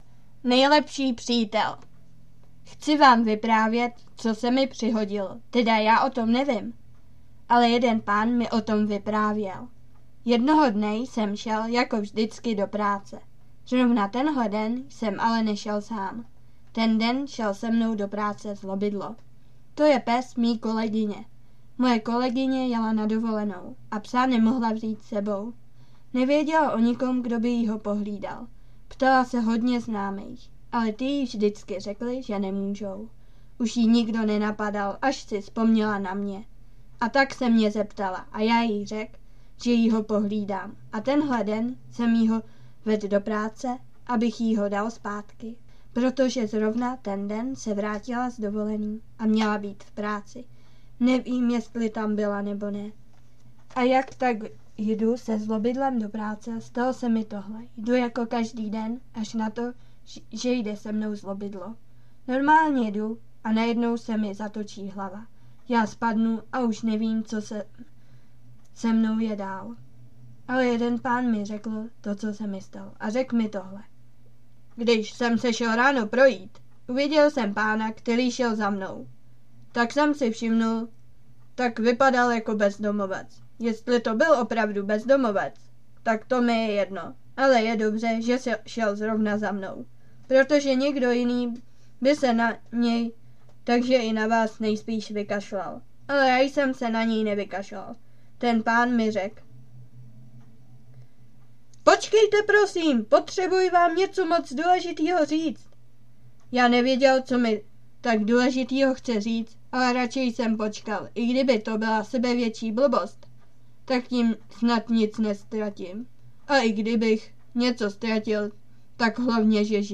kategorie povídky/ze života
mluvené